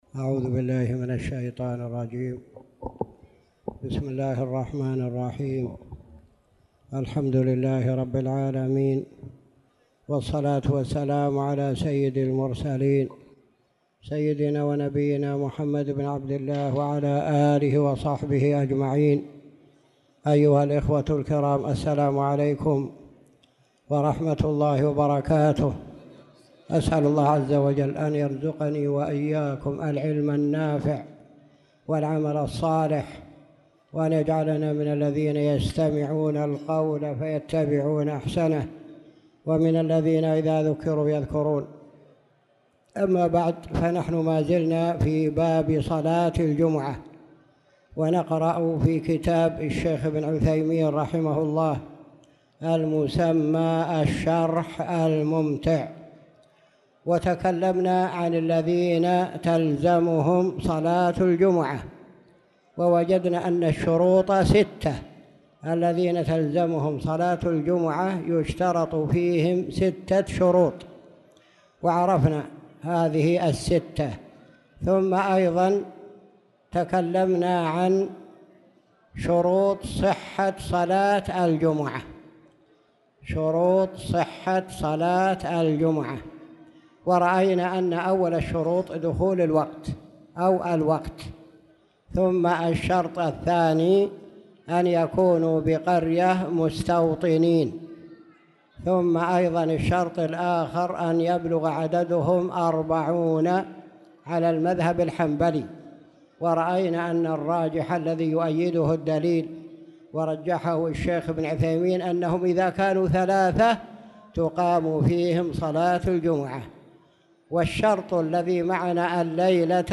تاريخ النشر ١٠ شعبان ١٤٣٧ هـ المكان: المسجد الحرام الشيخ